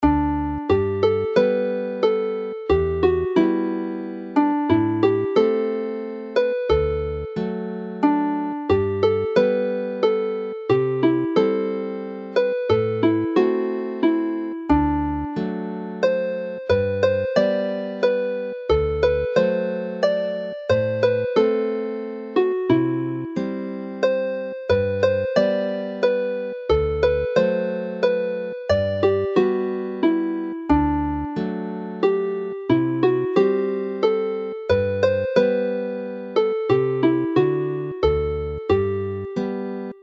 This set is based on the lovely air Tŷ Fy Nhad (my farther's house).